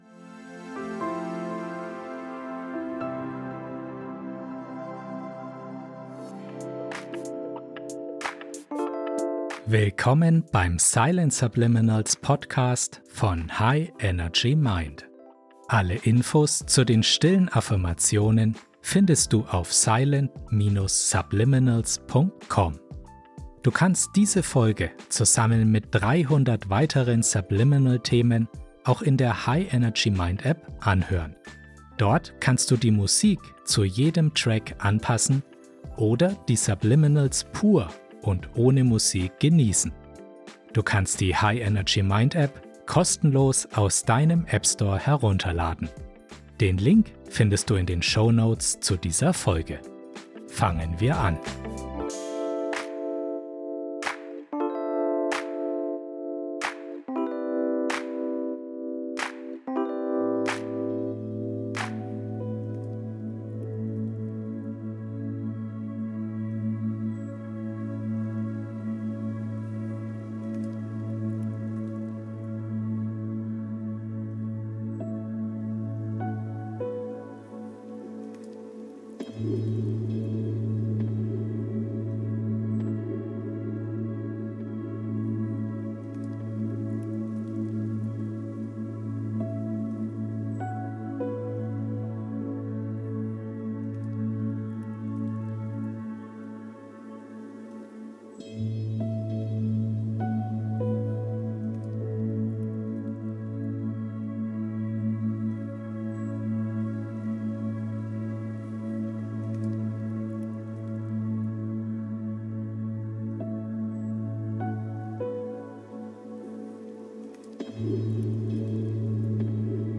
beruhigender 432 Hz Musik